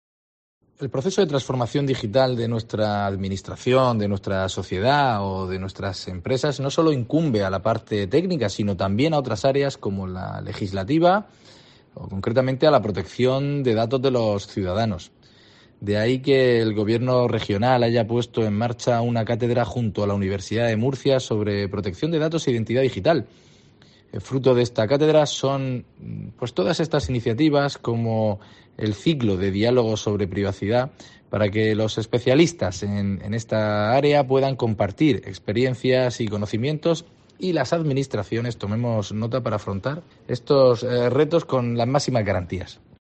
Javier Martínez Gilabert, director general de Informática y Transformación Digital